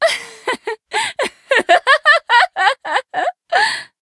MissFortune.laugh.jp5.mp3